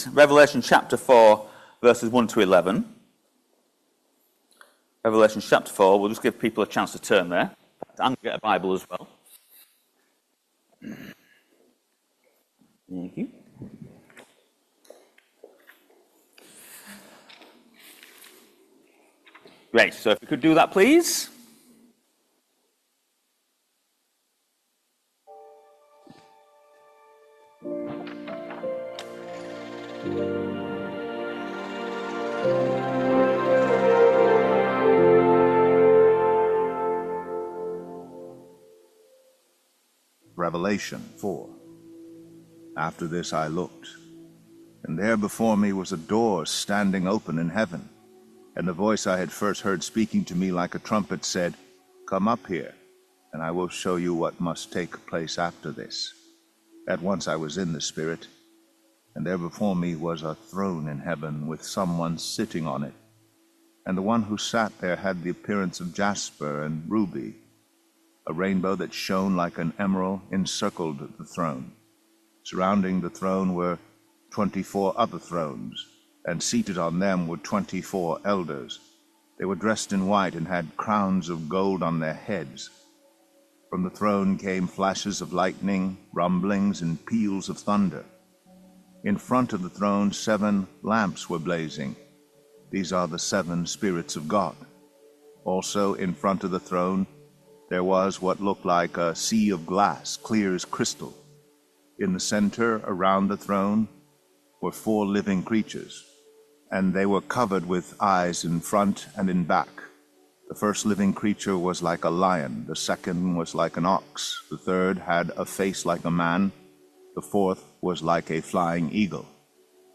Revelation 4vv1-11 Service Type: Sunday Morning All Age Service Topics